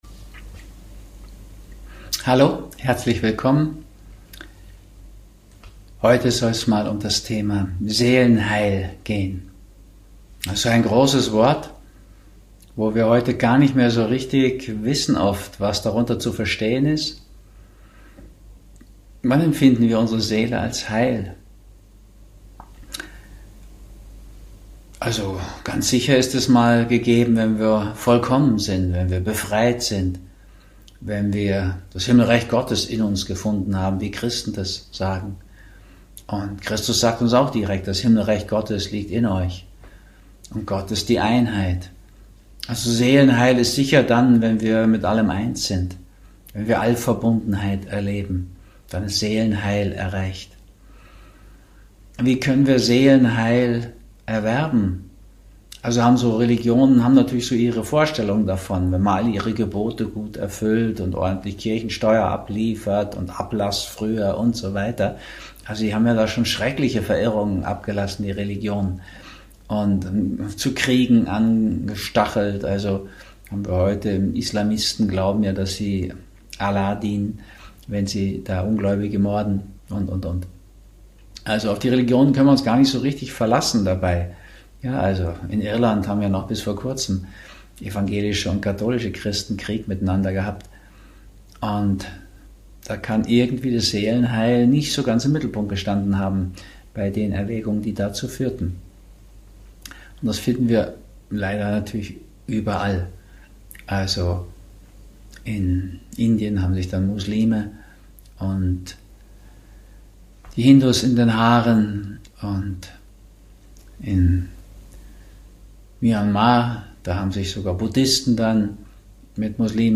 Seelenheil und Menschenheil aus diversen Perspektiven - auch aus der Sicht der Religionen. Er spricht in diesem spannenden Vortrag über Symptome, Gesundheit und wie Mensch aus seiner Perspektive heil und ganz werden kann.